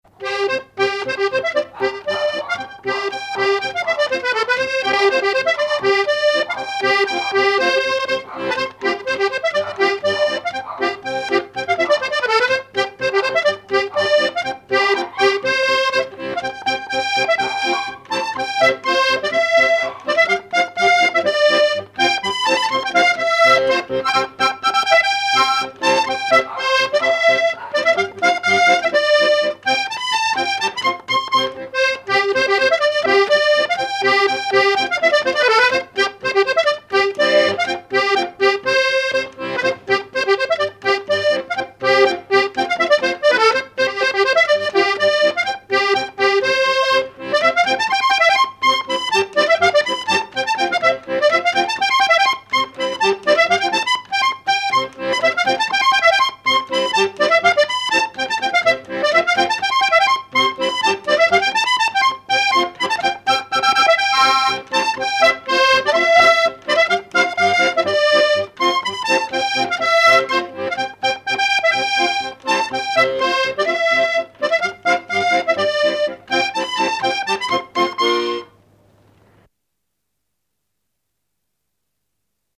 accordéon(s), accordéoniste ; musique traditionnelle
Luceau
danse : polka
Répertoire des danses à l'accordéon diatonique